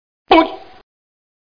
00512_Sound_bonk.mp3